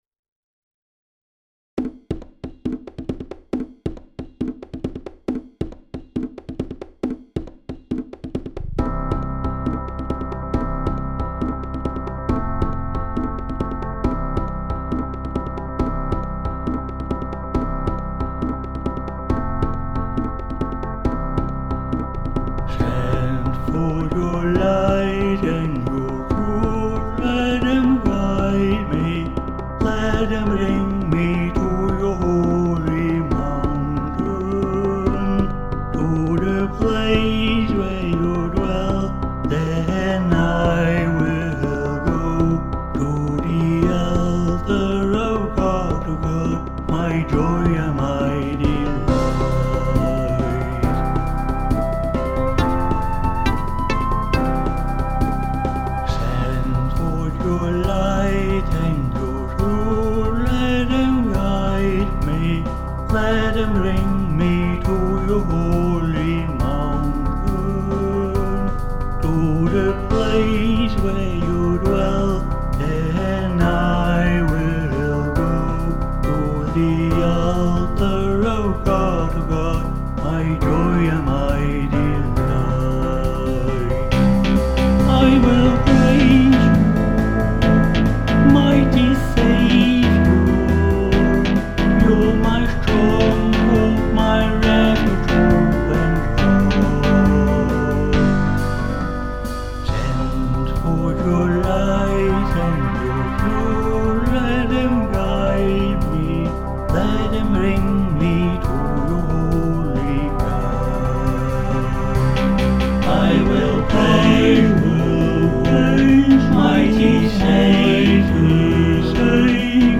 MP3 (SATB)